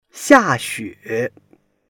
xia4xue3.mp3